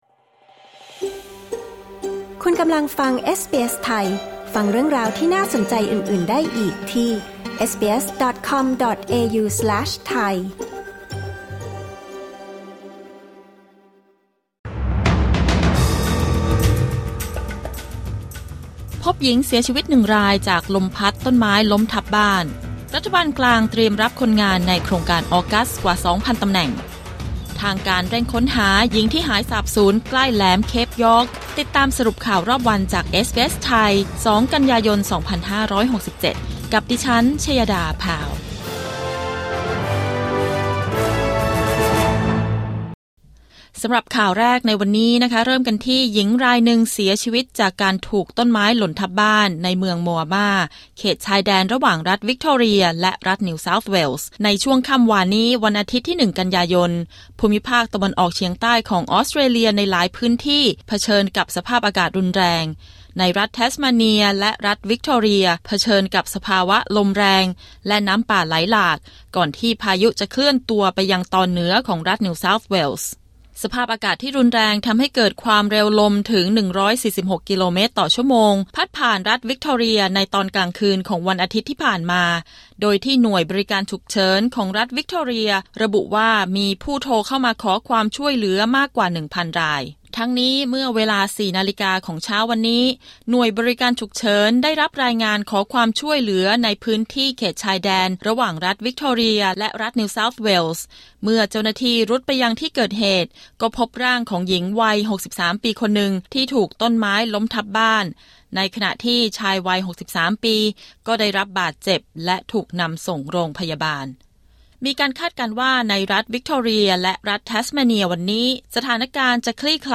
สรุปข่าวรอบวัน 2 กันยายน 2567